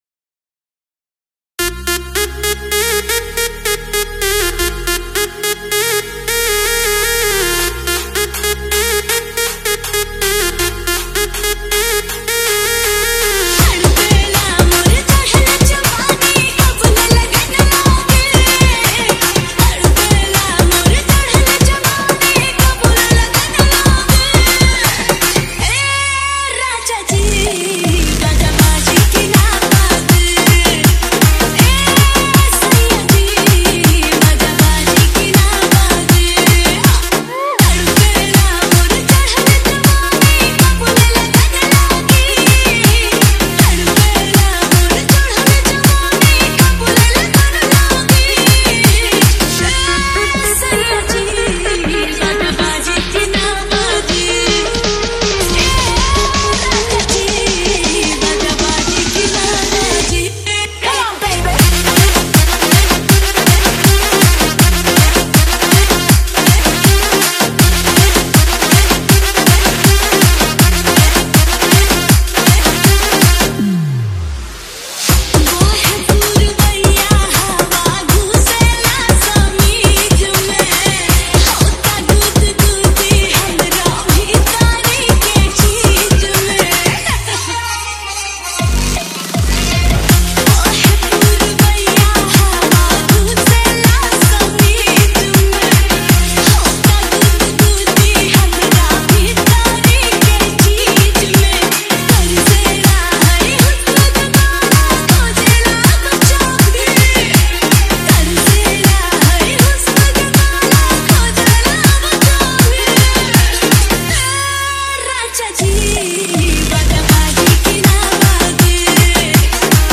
Category : Bhojpuri Remix Song